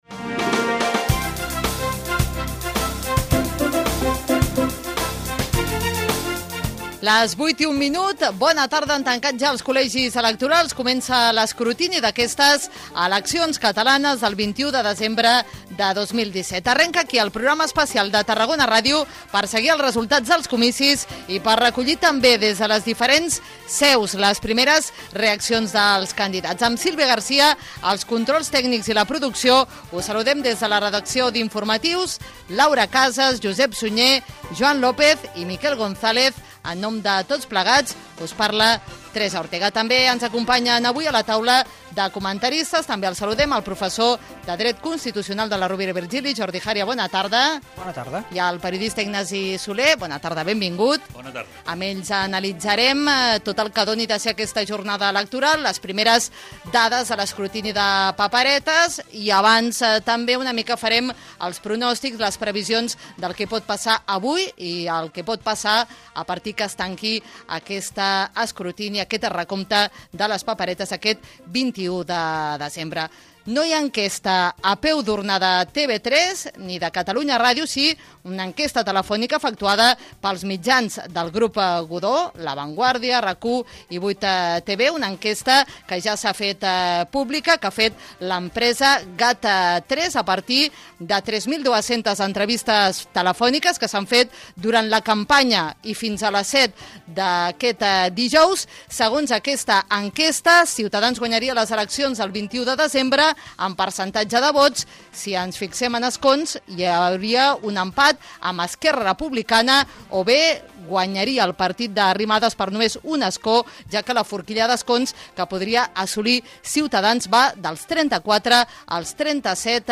Hora, presentació del programa especial informatiu, equip i especialistes. Enquestes sobre els possibles resultats de les eleccions. Connexió amb un col·legi electoral del centre de Tarragona
Informatiu
FM